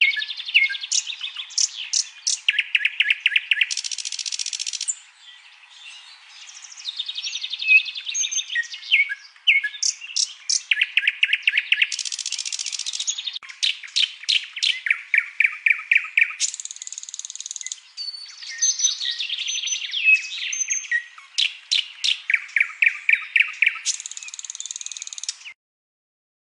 悦耳的夜莺叫声